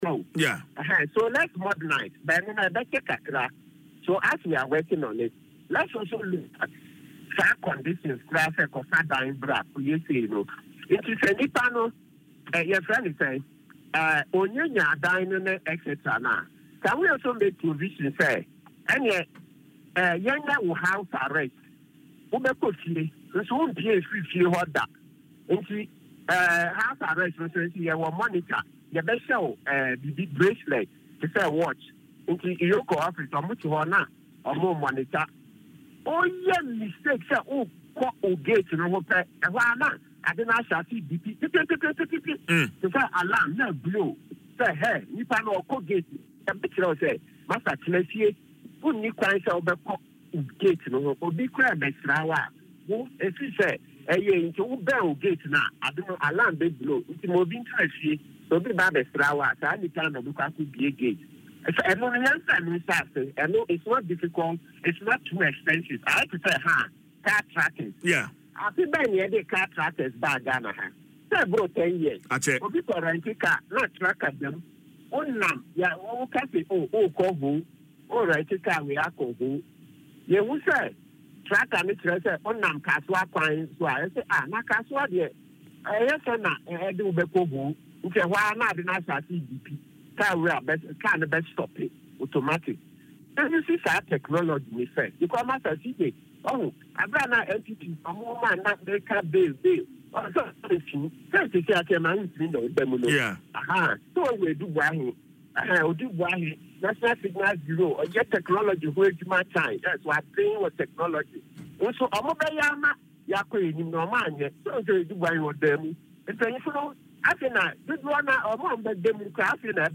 interview
morning show